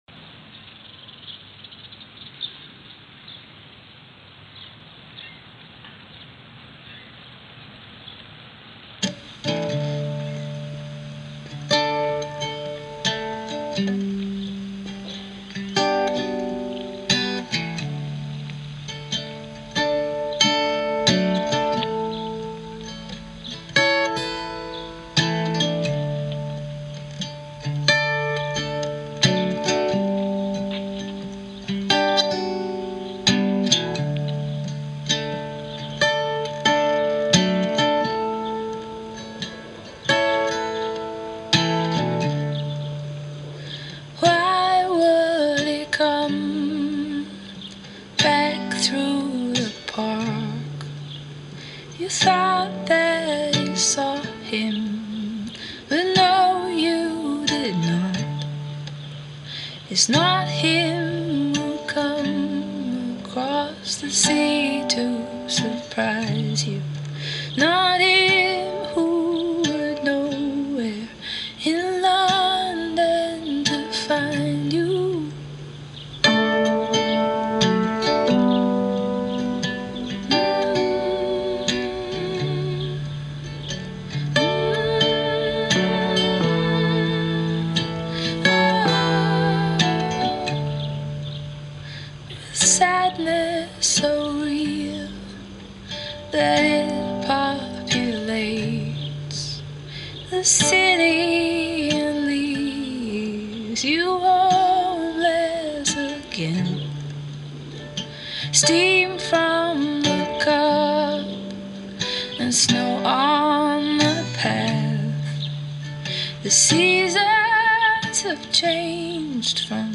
Posted in Indie Rock on May 7th, 2007 1 Comment »